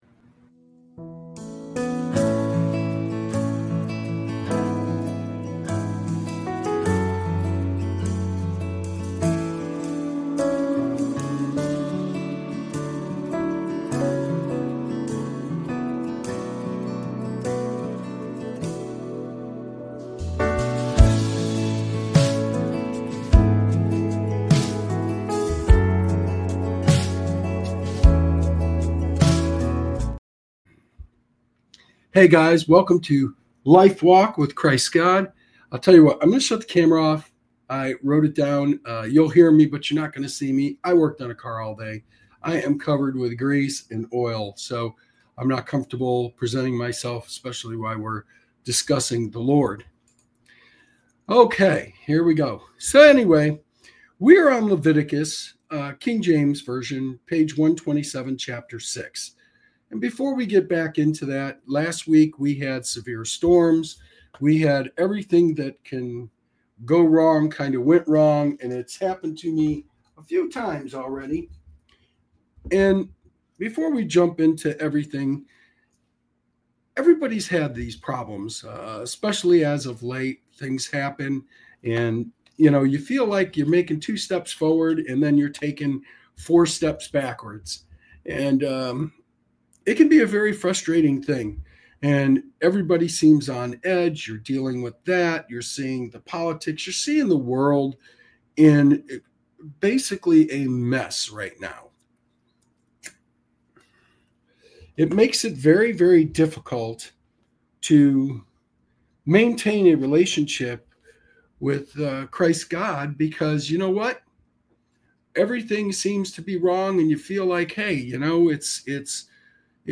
This show offers a reading into the teachings of Jesus Christ, providing insights into the Bible.